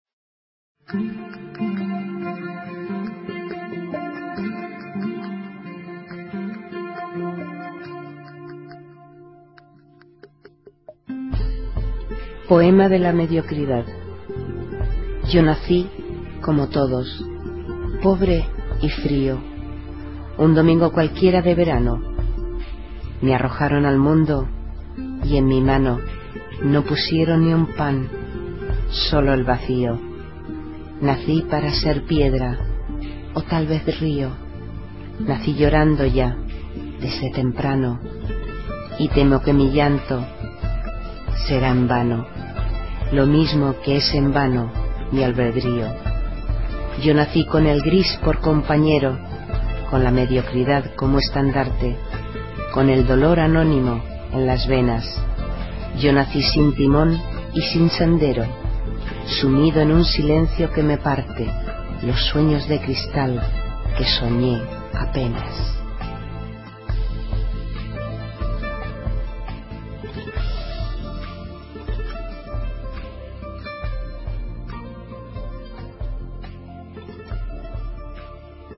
Inicio Multimedia Audiopoemas Poema de mediocridad.
(Recitado por